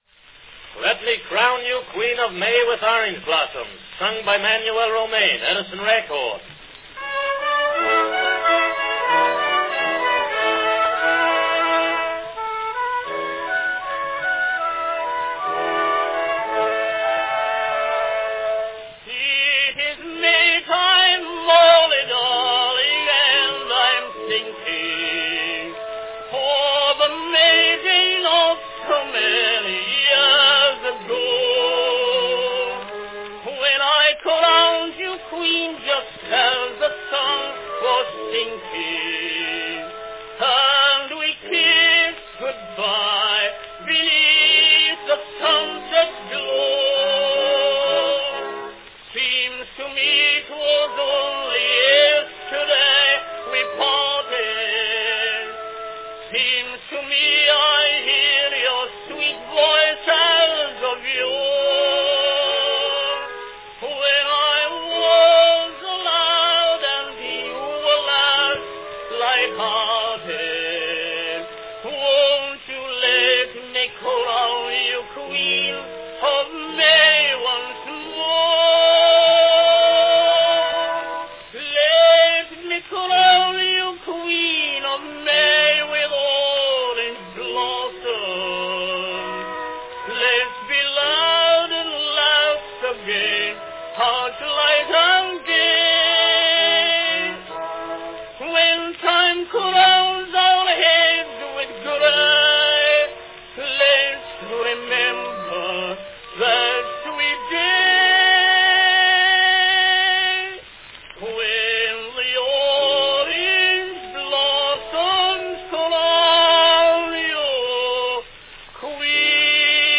A springtime love song
Category Tenor
A picturesque love ballad that vividly recalls Record 9728, "When It's Moonlight, Mary Darling, 'Neath the Old Grape Arbor Shade."
Orchestra accompaniment; music, J. Fred Helf; words, Bartley Costello; publishers, Helf & Hager Co., New York.
two-minute wax cylinder recordings